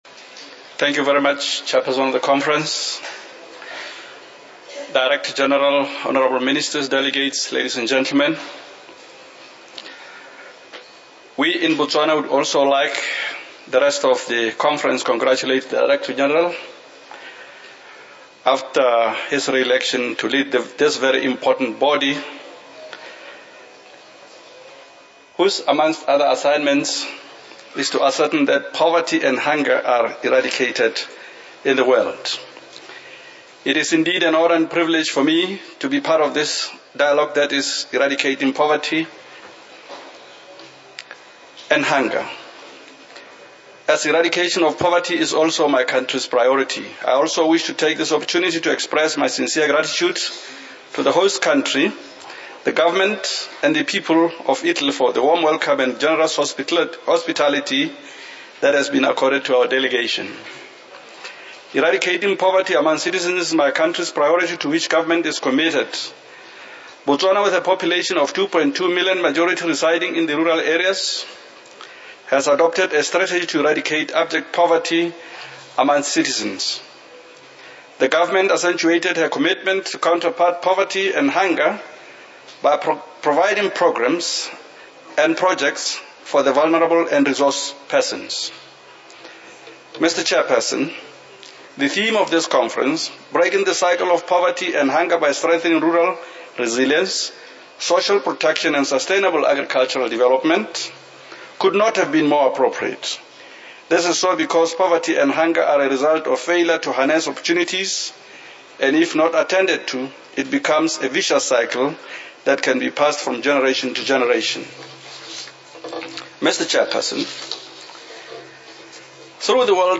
FAO Conference
Statements by Heads of Delegations under Item 10:
The Honourable Patrick Pule Ralotsia, Minister for Agriculture of Botswana